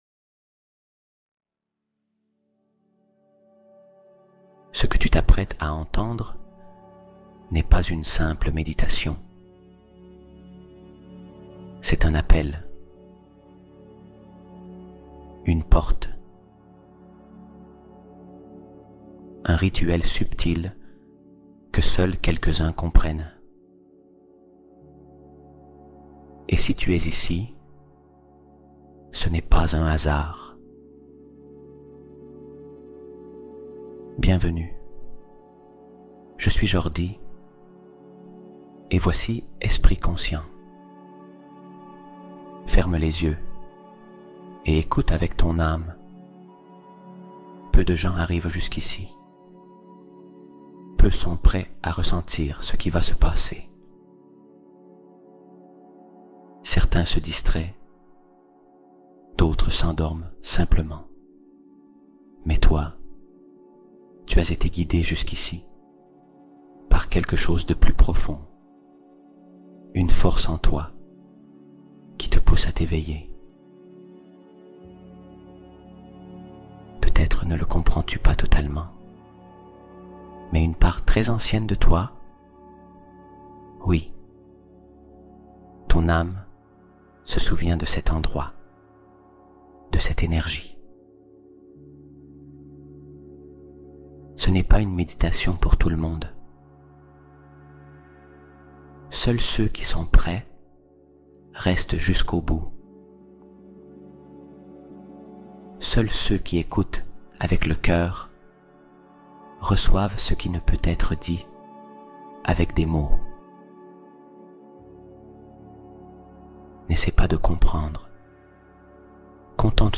Choisi par l'Âme | 432 Hz pour réveiller ta mémoire sacrée oubliée
Méditation Guidée